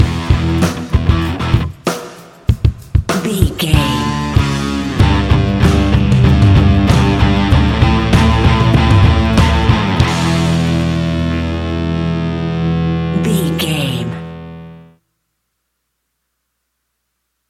Ionian/Major
hard rock
distortion
instrumentals